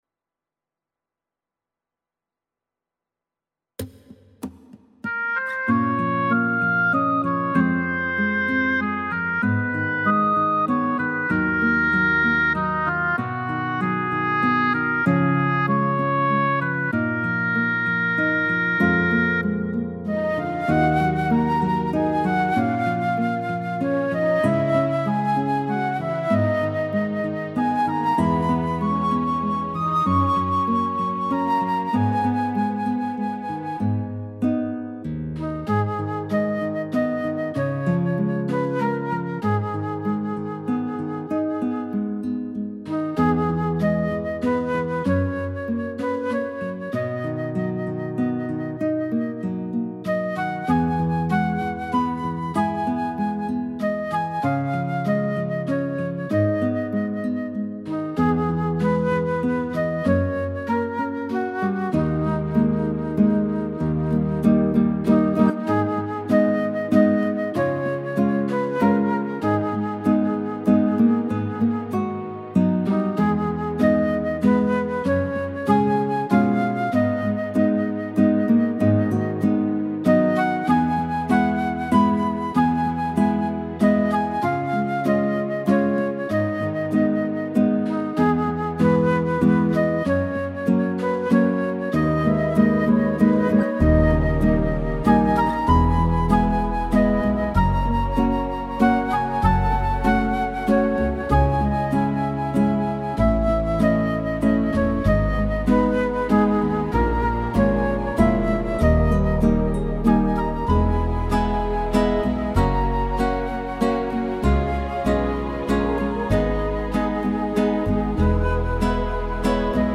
•   Beat  05.
(G#m)